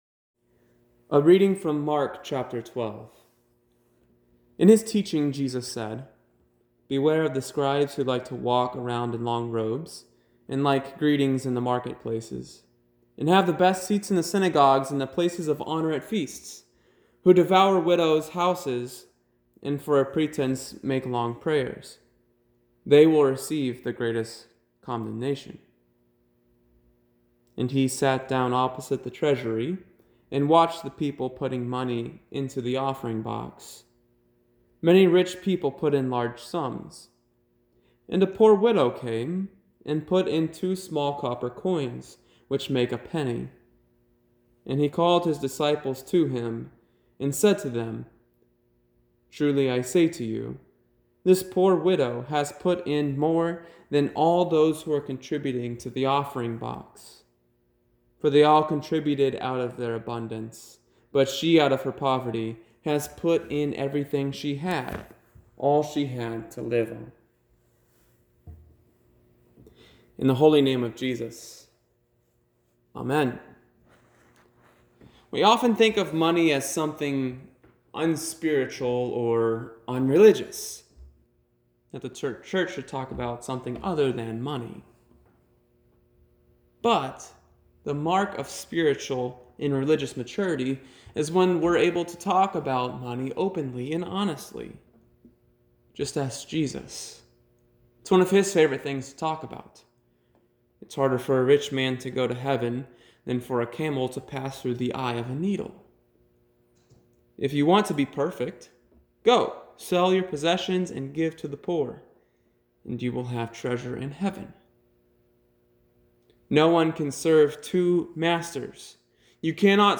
Mid-Week Service 2 Wednesday, March 11, 2020 Text: Mark 12:38-44
Recent Sermons